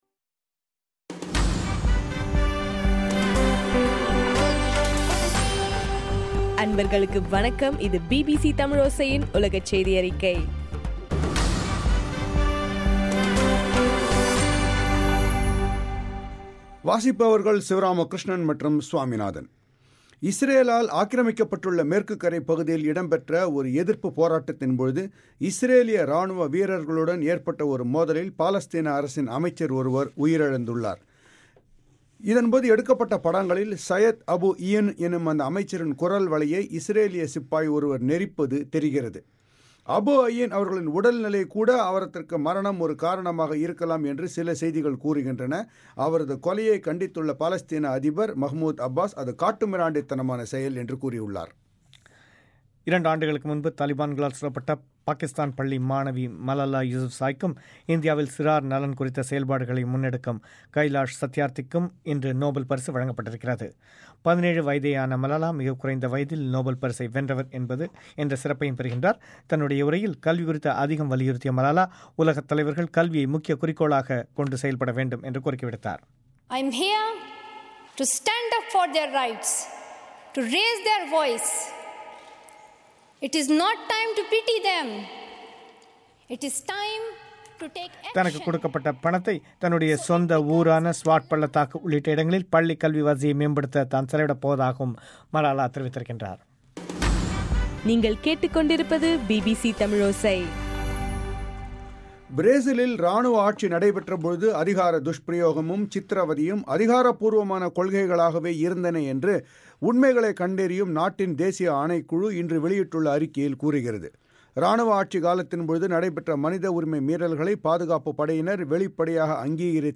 டிசம்பர் 10 2014 பிபிசி தமிழோசையின் உலகச் செய்திகள்